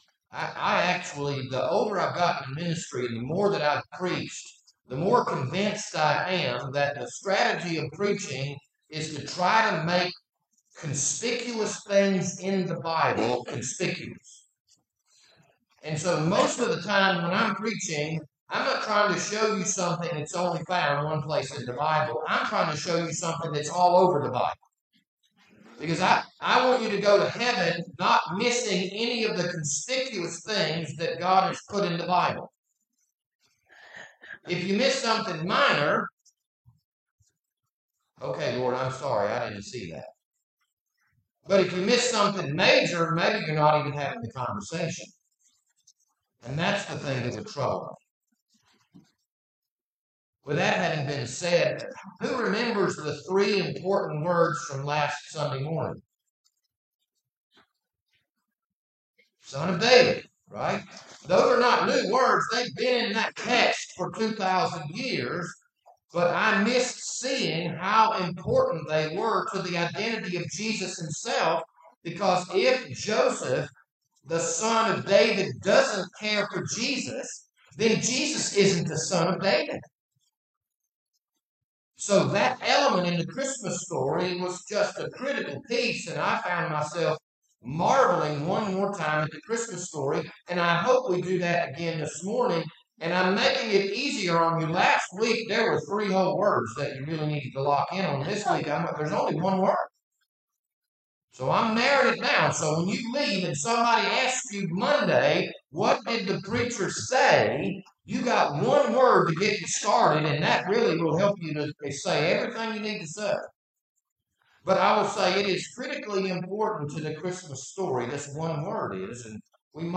This Sunday morning sermon was recorded on December 10th, 2023.